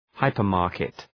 {‘haıpər,mɑ:rkıt}